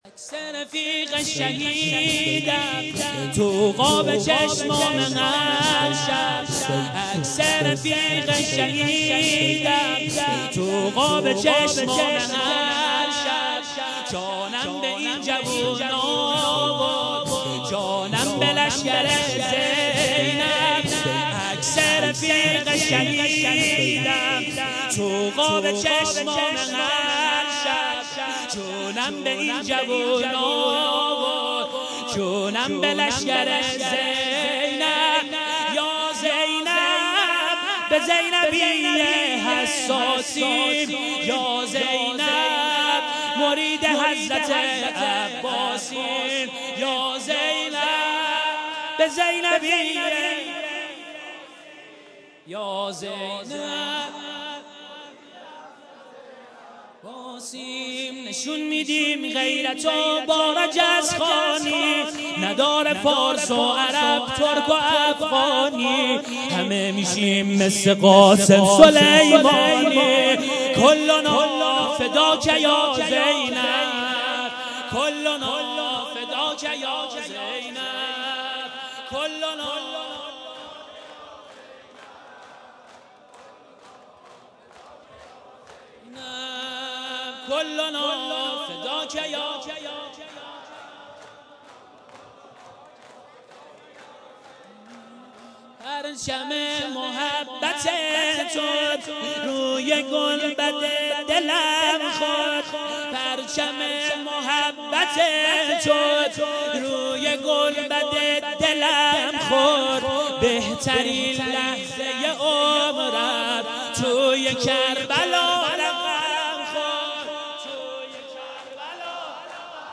شور - عکس رفیق شهیدم تو قاب چشمامه هرشب